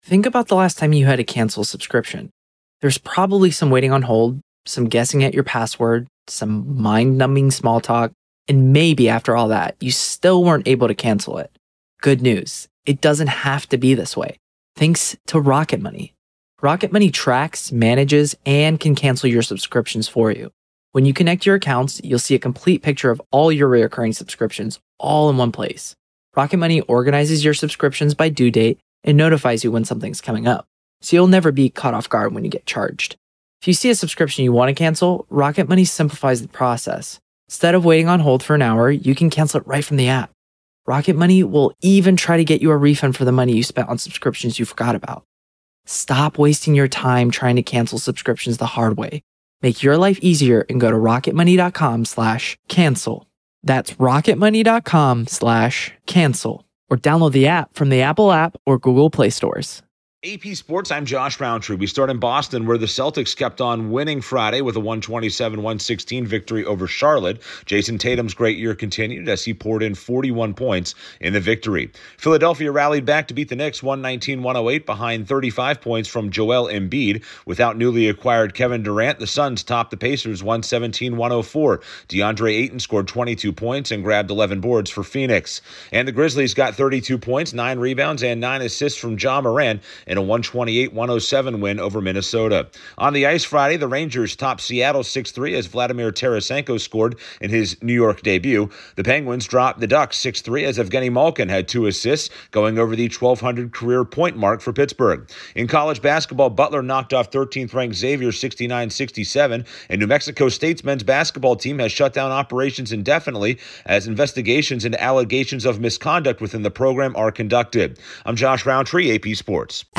The Celtics, Grizzlies 76ers and Suns all win, the Rangers got a nice debut from Vladimir Tarasenko, Evgeni Malkin hit a milestone for the Penguins, a college hoops upset and a men's program shuts down. Correspondent